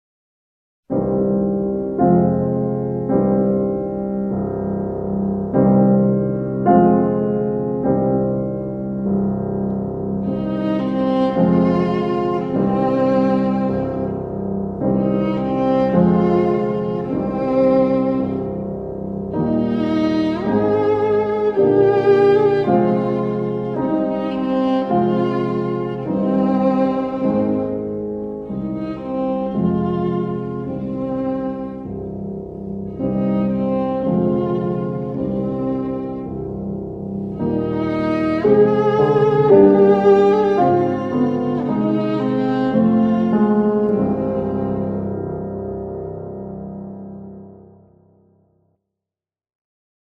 volgapiano.mp3